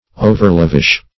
\O"ver*lav"ish\